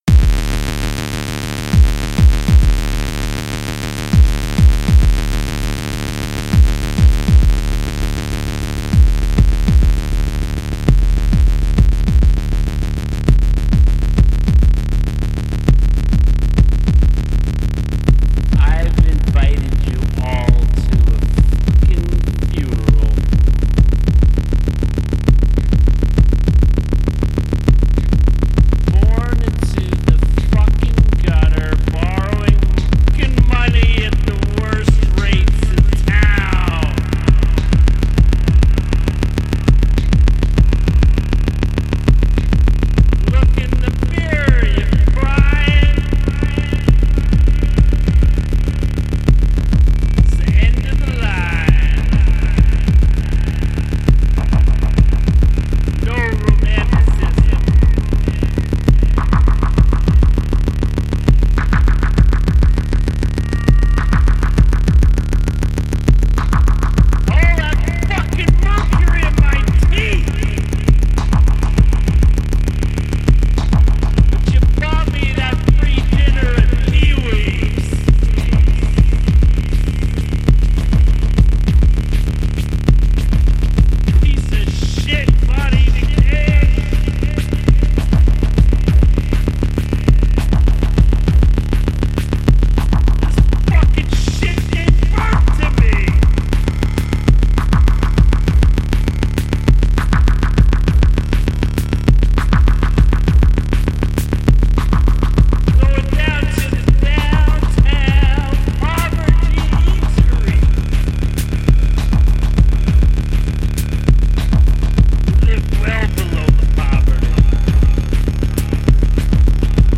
punk/industrial/scum electronics
Electro Electronix Techno